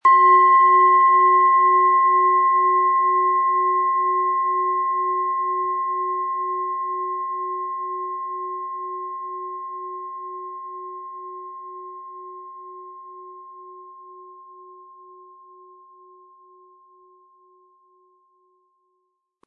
Wie klingt diese Planetenschale® DNA?
Im Sound-Player - Jetzt reinhören können Sie den Original-Ton genau dieser Schale anhören.
PlanetentonDNA
SchalenformBihar
MaterialBronze